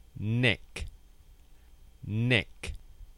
Haz clic para escuchar la pronunciación de las palabras: